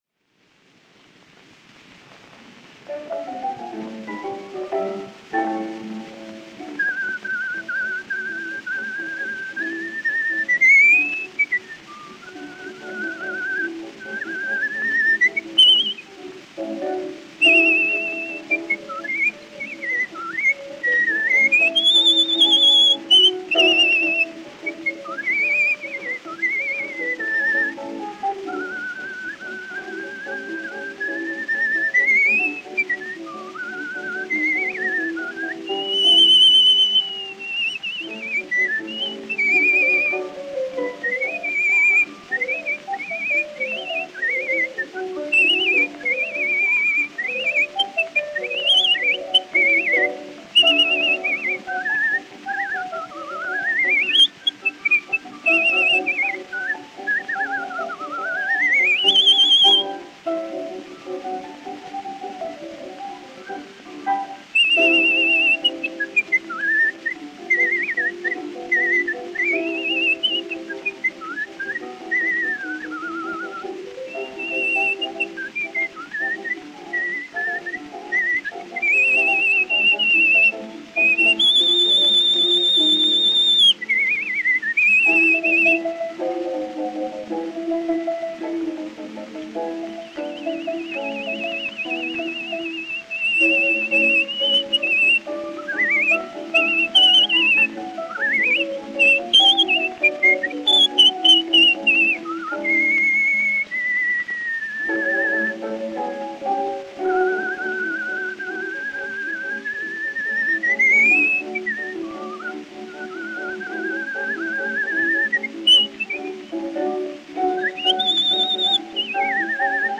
A champion whistler, he performed a range of bird calls, light classics and popular airs to enthusiastic audiences.
Music by Luigi Arditi recorded by Pathé Frères 29001, about 1915